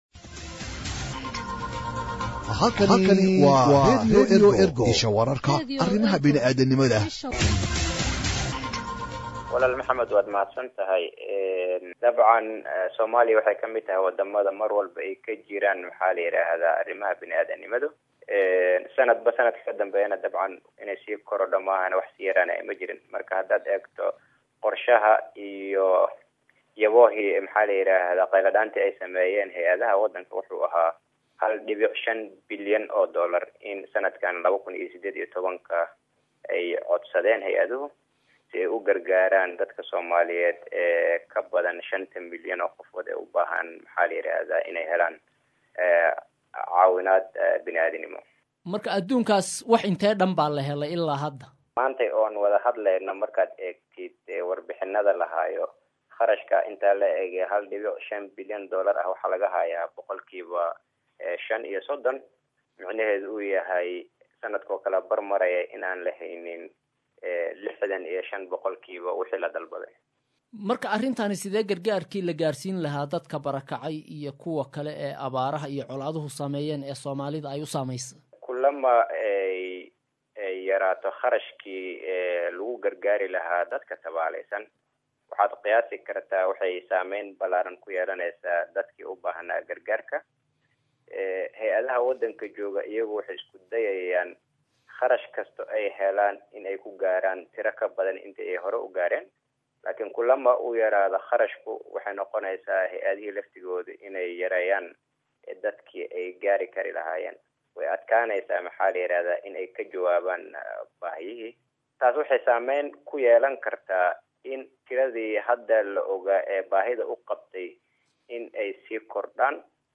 NRC-WARAYSI-SAX.mp3